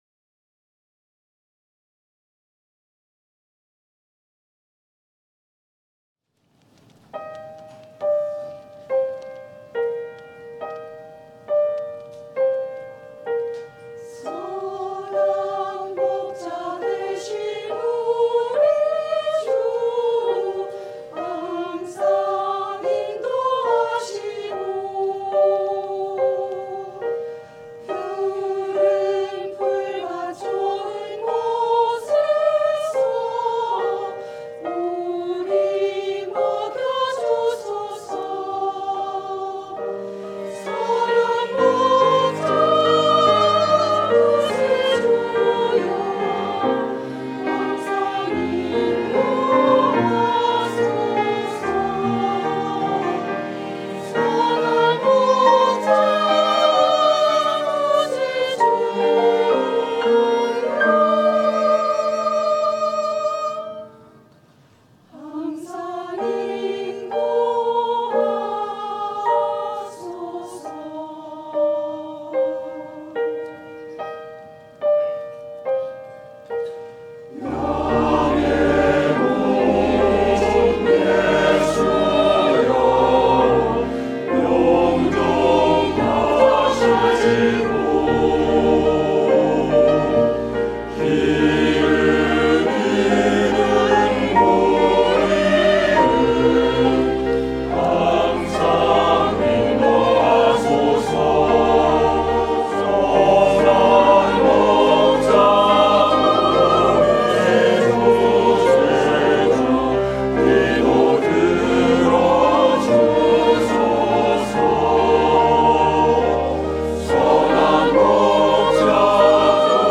시온